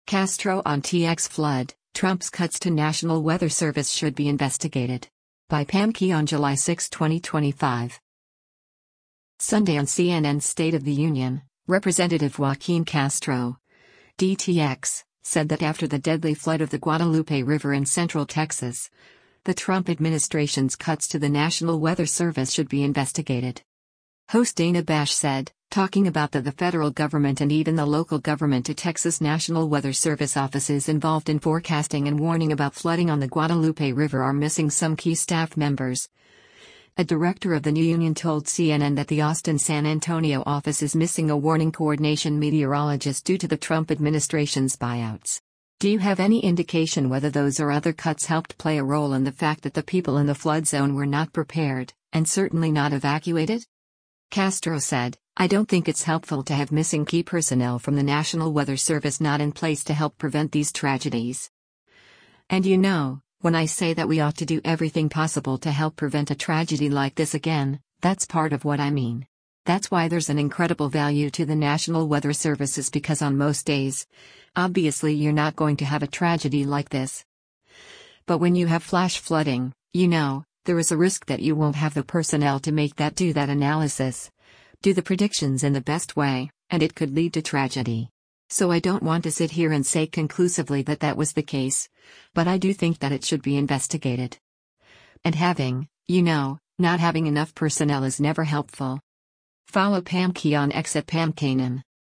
Sunday on CNN’s “State of the Union,” Rep. Joaquin Castro (D-TX) said that after the deadly flood of the Guadalupe River in central Texas, the Trump administration’s cuts to the National Weather Service should be investigated.